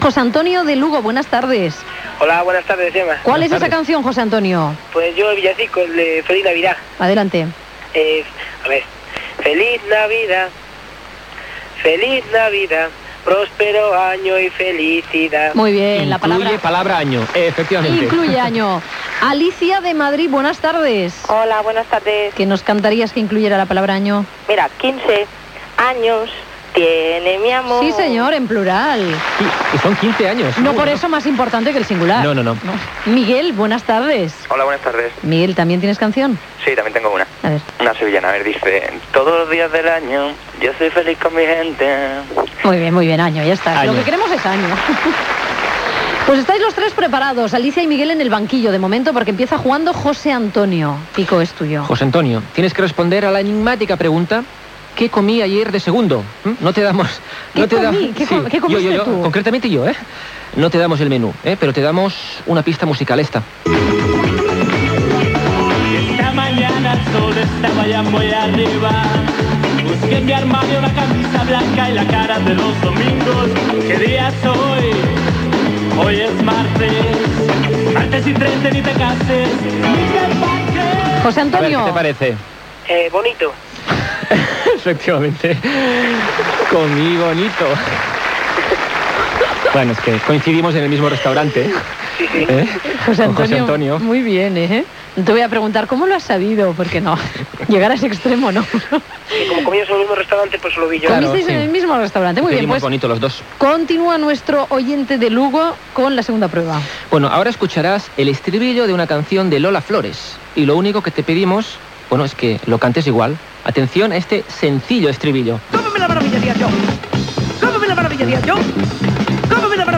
Concurs amb oients que canten cançons que incloguin la paraula "año" i després contesten les preguntes plantejades
Entreteniment